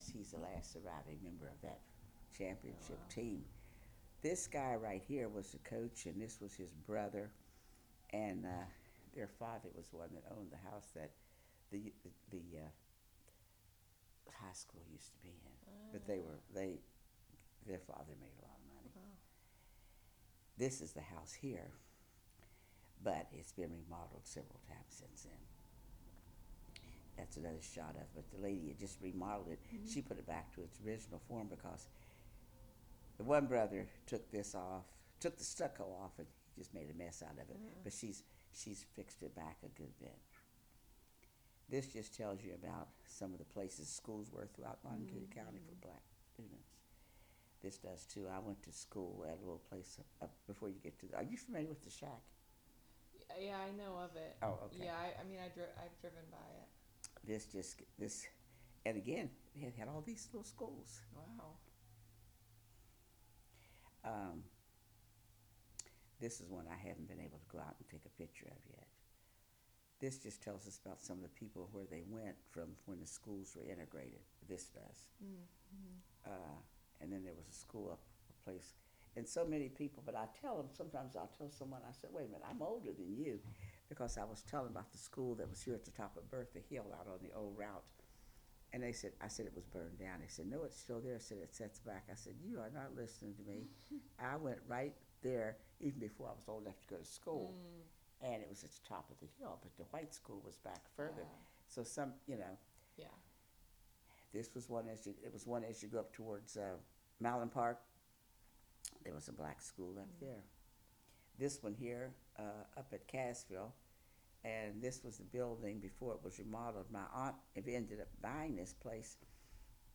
Oral history of Charlene Marshall, 3 of 3
This interview is part of a collection of interviews conducted with Scotts Run natives/residents and/or members of the Scotts Run Museum.